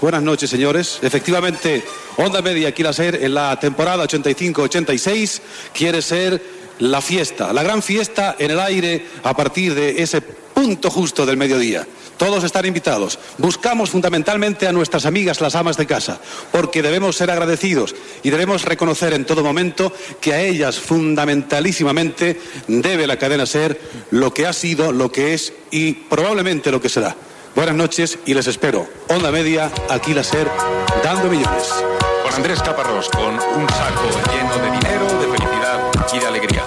Transmissió, des de l'Hipódromo de la Zarzuela de Madrid, de la Fiesta de la Cadena SER amb motiu de l'estrena de la nova programació.